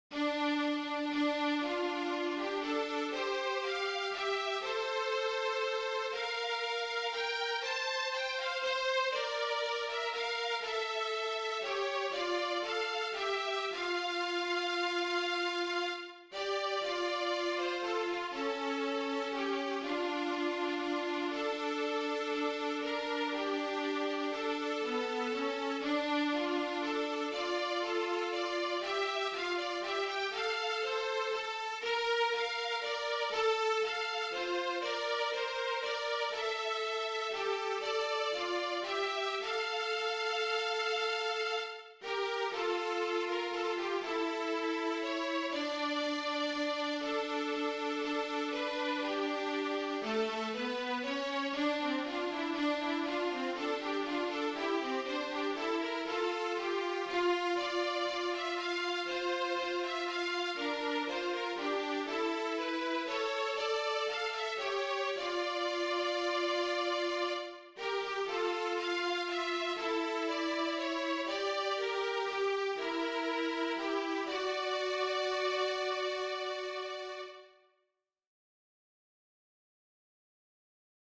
This is a violin duet obligato of That Easter Morn.
Voicing/Instrumentation: Violin Duet/Violin Ensemble Member(s) We also have other 19 arrangements of " That Easter Morn ".